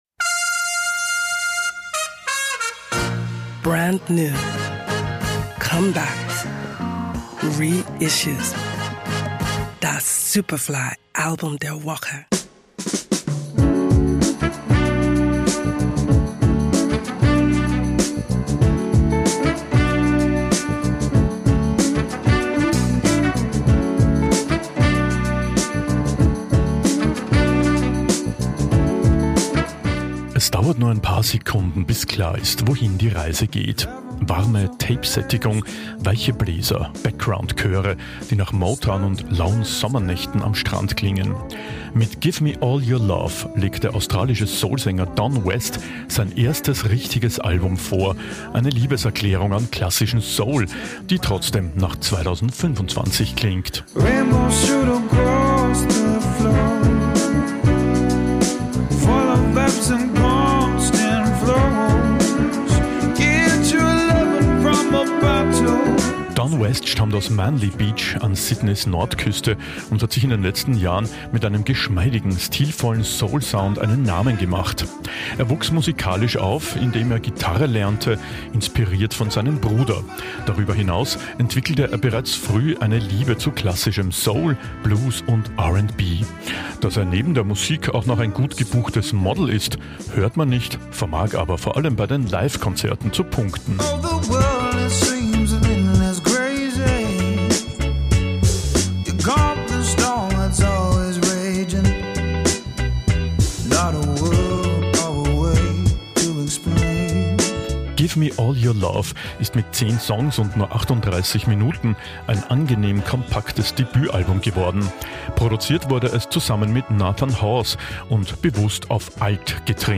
der australische Soul-Sänger
eine Liebeserklärung an klassischen Soul